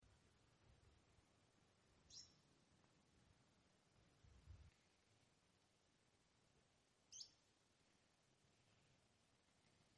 Canastero Coludo (Asthenes pyrrholeuca)
Fase de la vida: Adulto
Localización detallada: Campo de espinal a 15 km al sur de la ruta 35
Condición: Silvestre
Certeza: Vocalización Grabada
CanasteroColudo_dos-voces-cortas.mp3